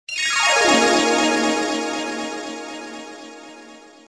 Thể loại nhạc chuông: Nhạc tin nhắn